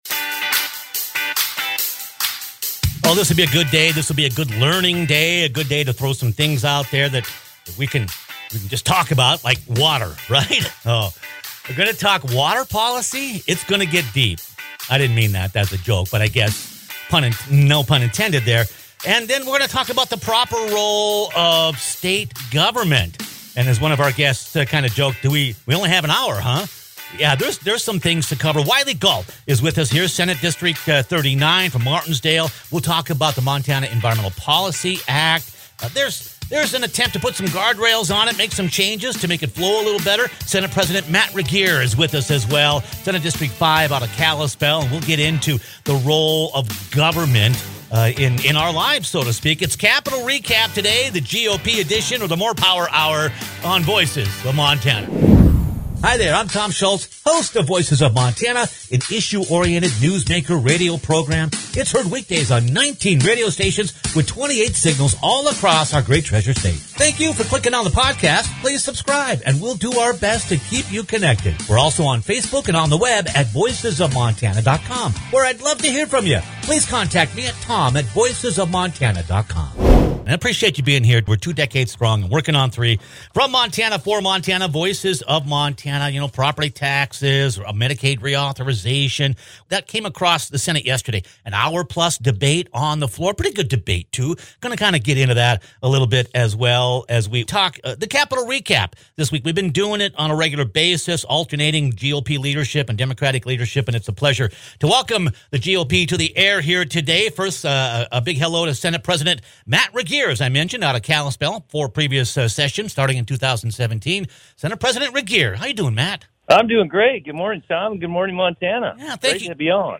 A great discussion this week with Senate President Matt Regier and Senator Wylie Galt.